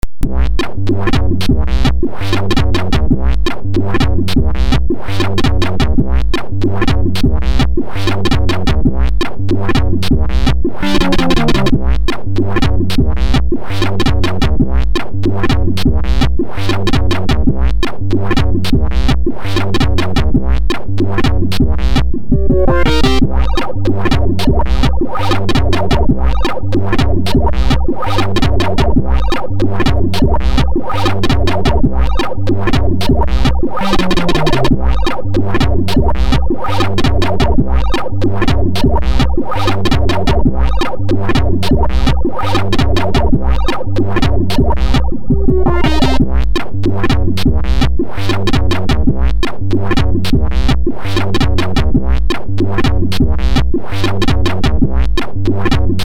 the third song i made today. it is for the 6581 SID chip. i hope you like it